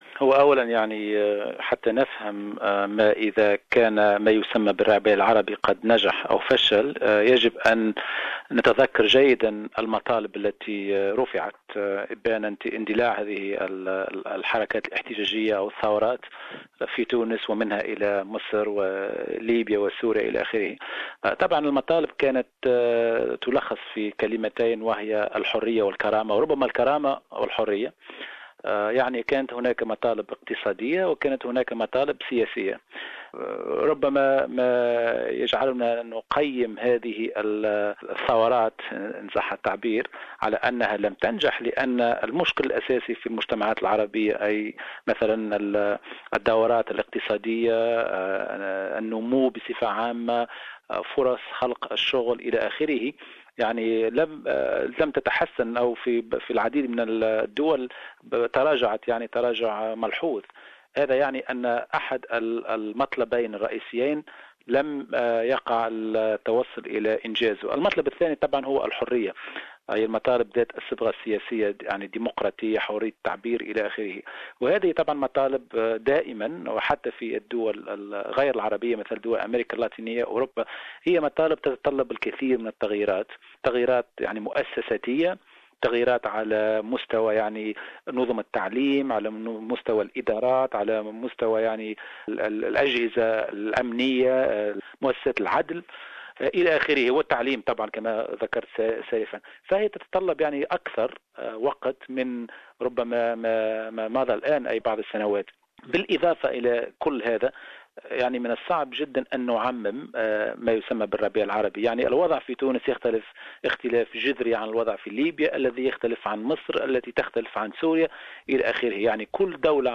المزيد في هذا اللقاء